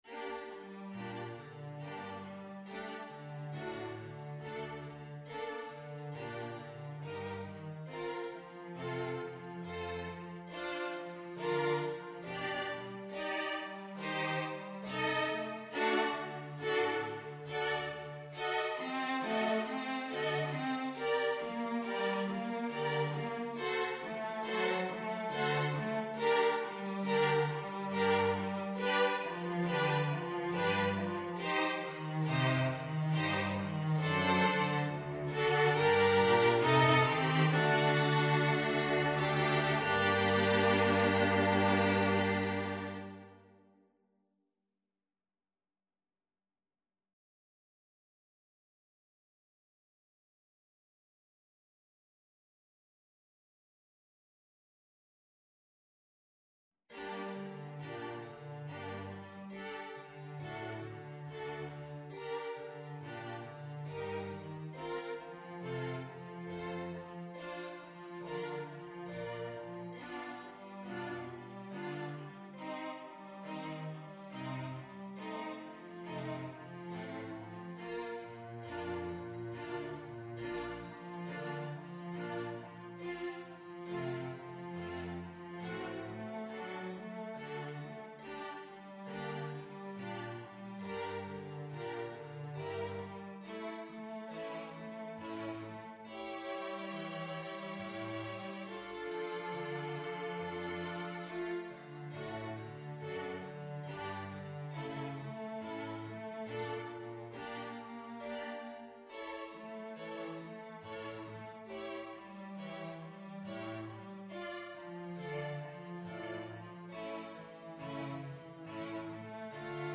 Andante stretto (=c.69)
Classical (View more Classical Soprano Voice Music)